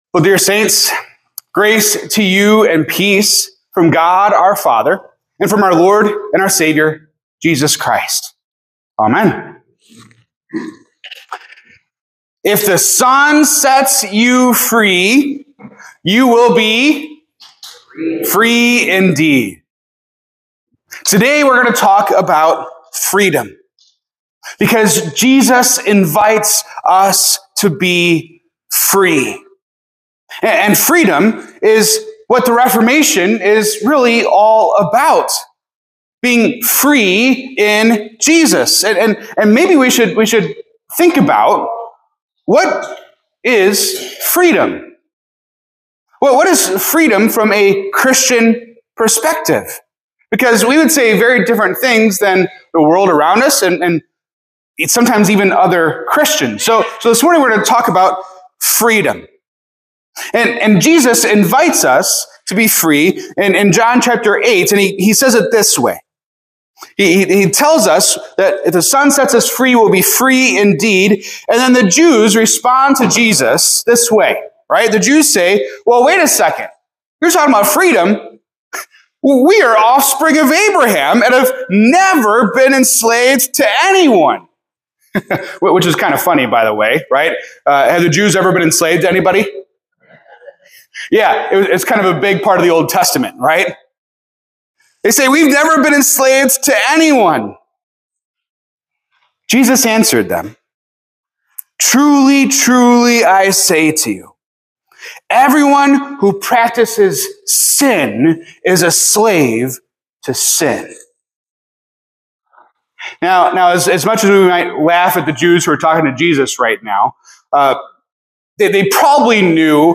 In this sermon for Reformation Sunday, true freedom comes only from Jesus Christ; all alternatives are slavery to sin. True freedom is found in abiding in His Word (John 8:31) and being made like Him, which is the realization of true humanity (1 John 3:2).